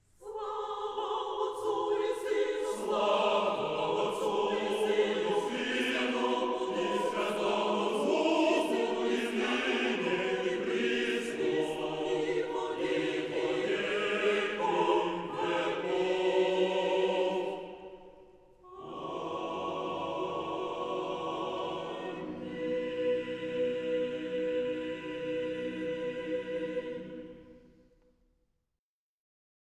Index of /Public/B-format/Recordings/Midas_Studios_Choir
midas_studios_choir_wave-ex-WXYZ.wav